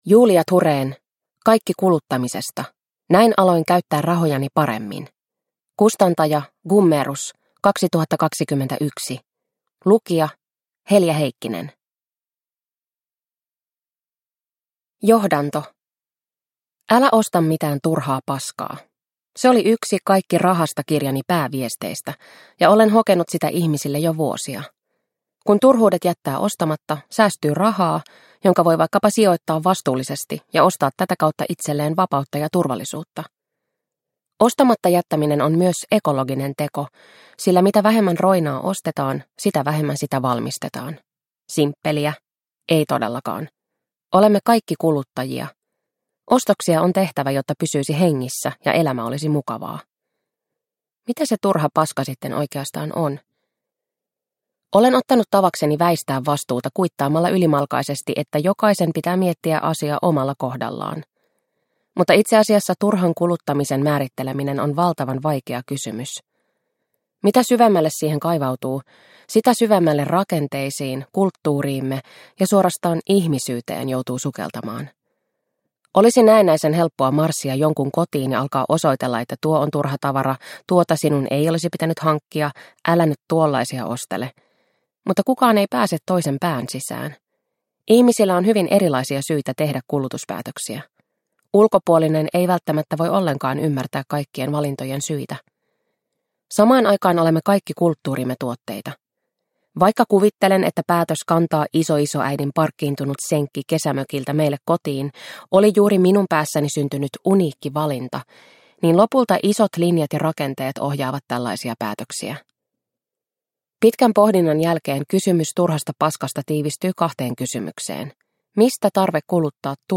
Kaikki kuluttamisesta – Ljudbok – Laddas ner